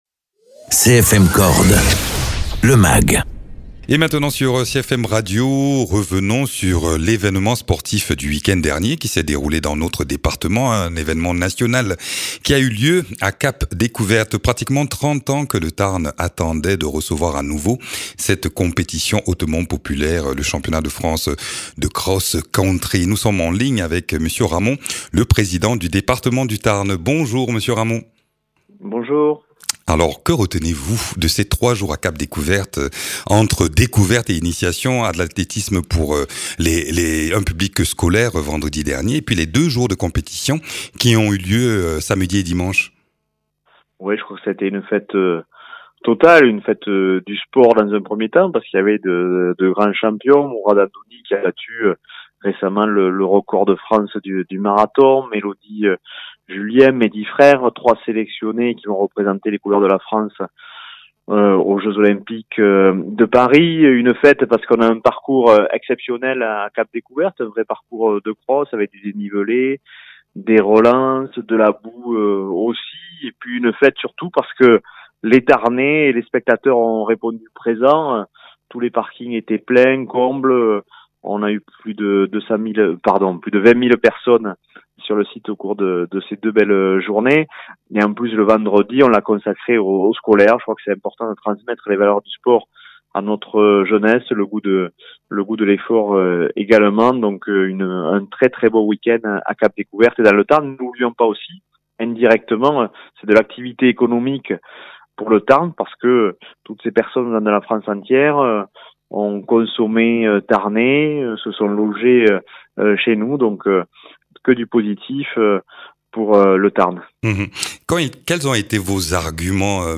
Interviews
Invité(s) : Christophe Ramond, Président du Département du Tarn.